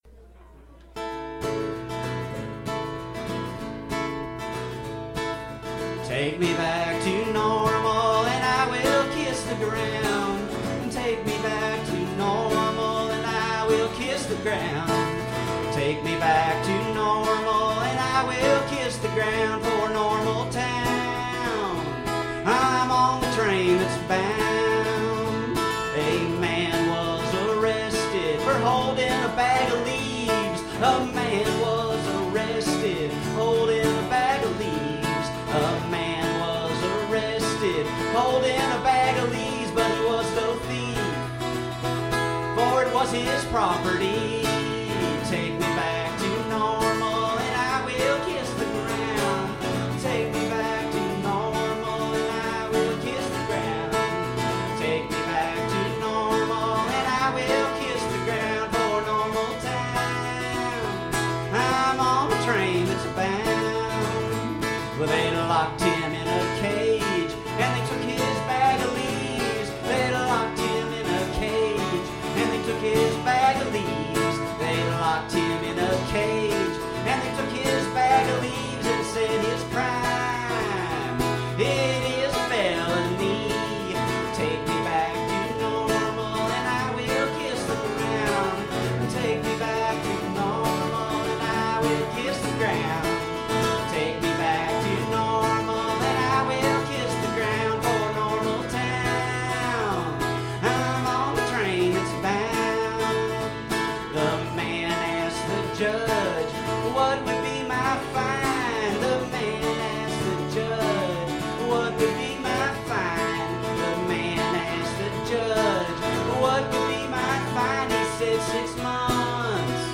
Capo 2, Play G